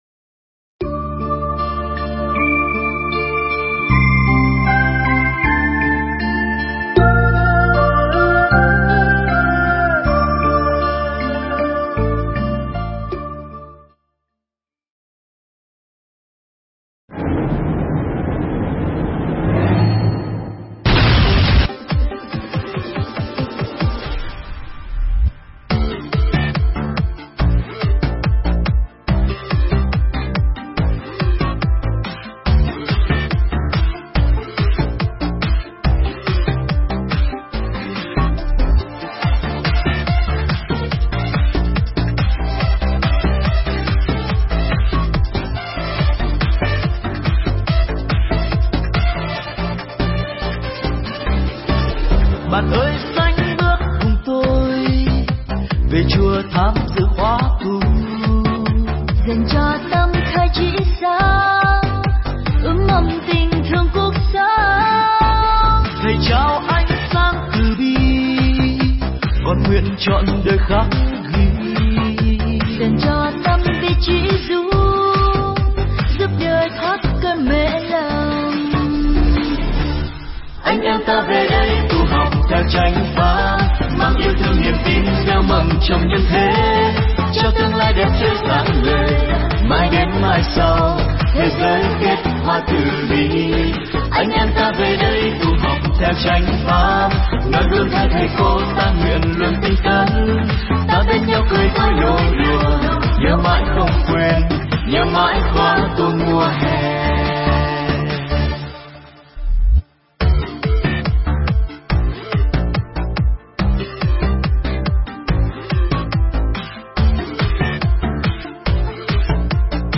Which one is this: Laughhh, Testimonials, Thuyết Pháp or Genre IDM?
Thuyết Pháp